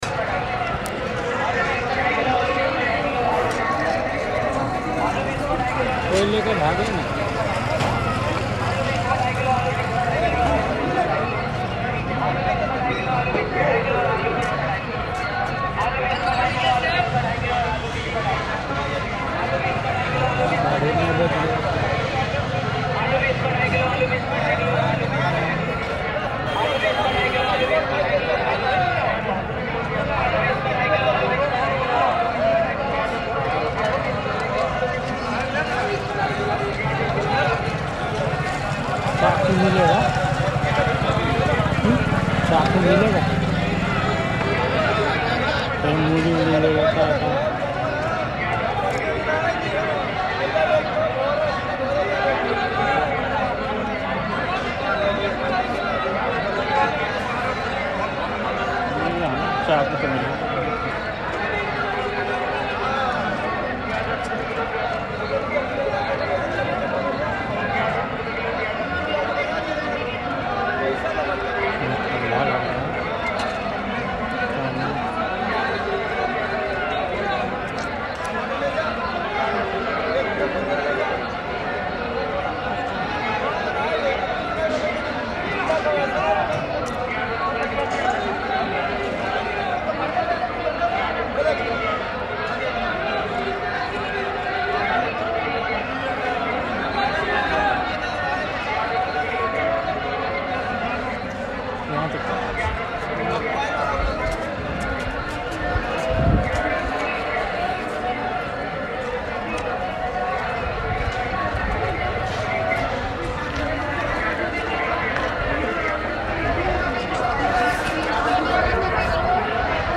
The recording is made one evening on a busy street in the Wazirpur Industrial Area. Vendors walk by, selling all sorts of things from clothes, slippers, utensils, cosmetics, old radios, meat, vegetables, and bangles. Most vendors have smalls mics or recorded tapes through which they advertise their prices. One such vendor is heard through a mic saying ‘Pyaaz das rupiya kilo’ (Onions 10 rupees per kg), whose sound by the end gets mixed with its own organic delay.